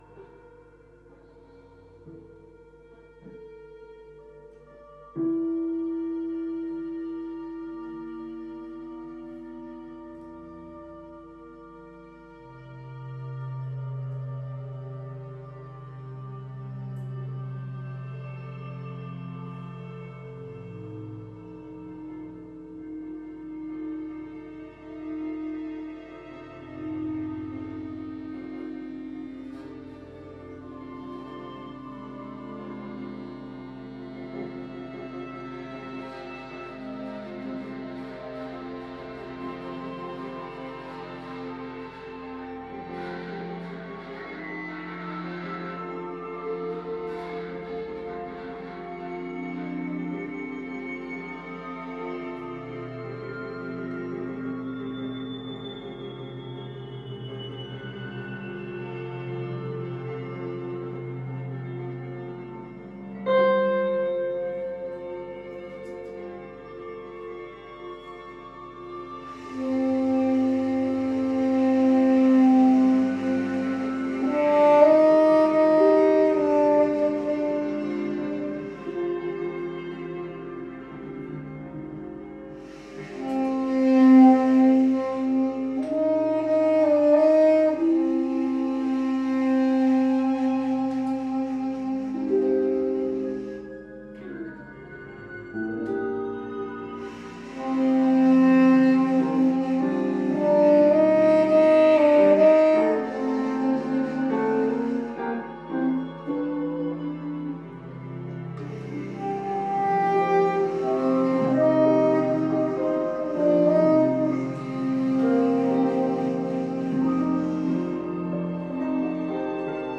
Saxophon/Samples
E-Gitarre